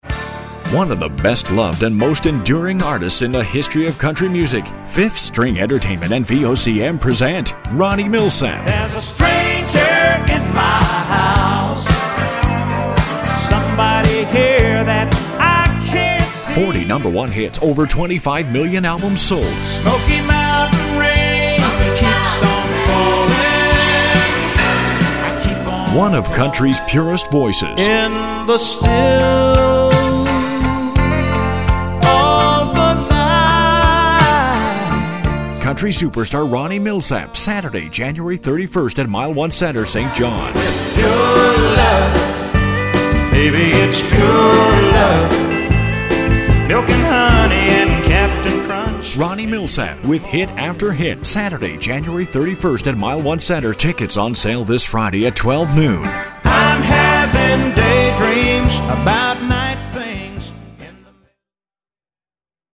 60 Second Concert Promo